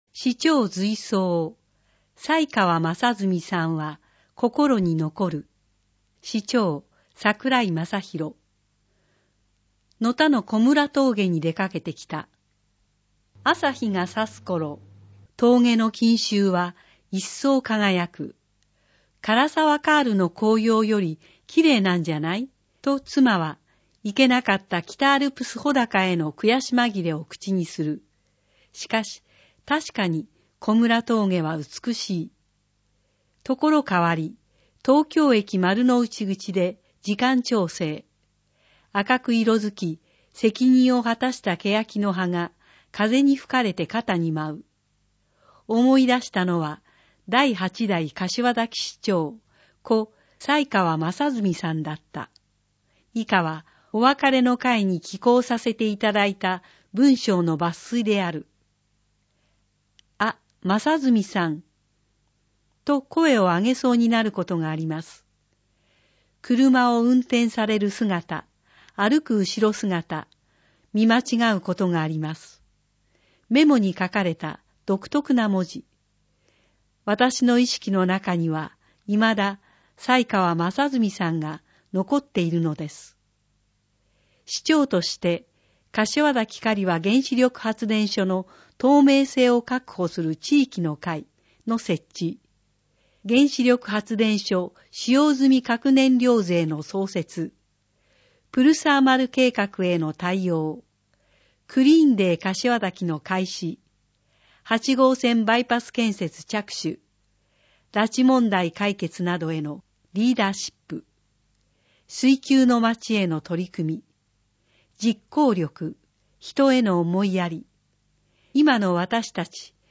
広報かしわざきの点訳・音訳 目の不自由な方のために、点字や音声による広報も用意しています。
音訳広報